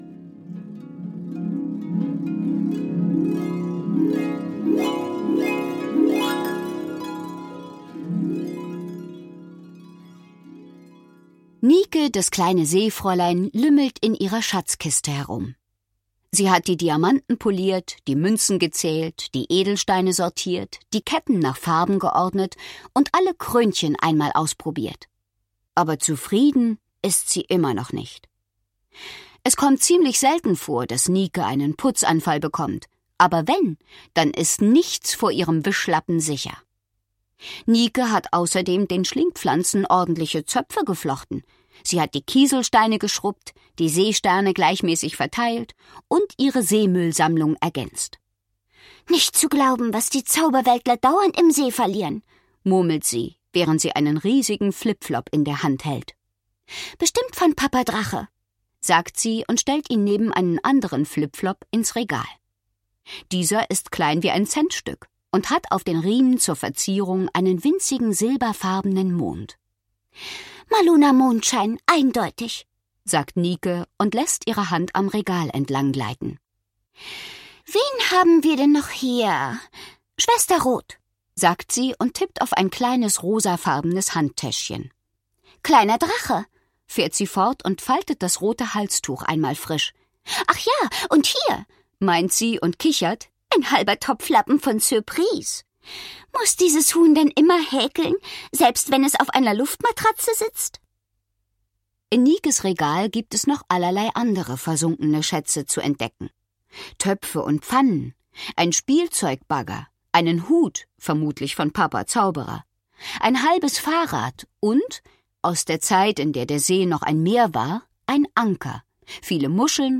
Schlagworte Einschlafen • Fantasy • Feen • Feen; Kinder-/Jugendliteratur • Gute-Nacht-Geschichten • Hörbuch; Lesung für Kinder/Jugendliche • Magie • Maluna Mondschein • Zauberei